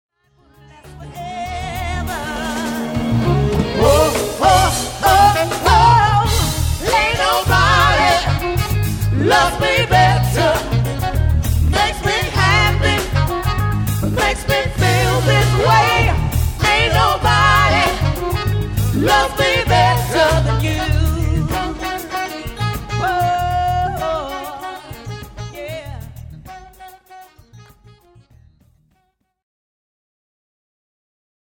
Popular, Funk, Dance